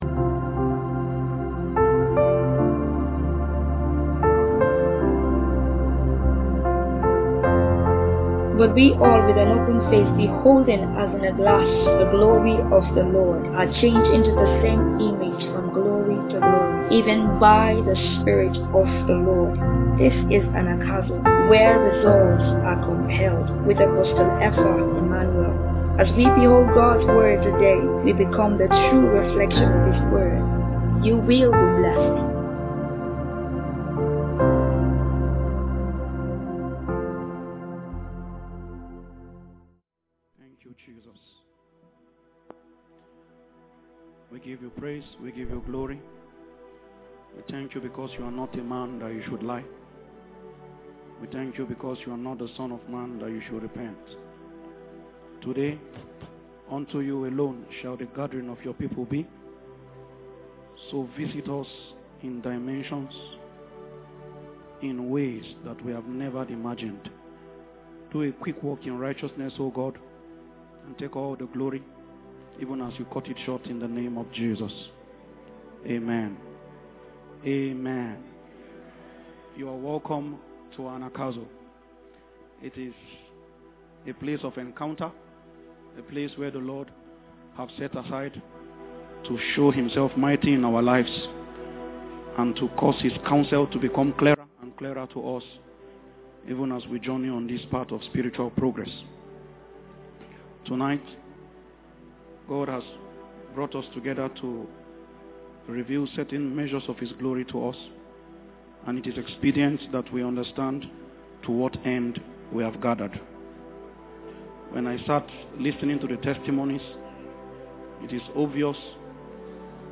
Sermon | Anagkazo Mission International | Anagkazo Mission International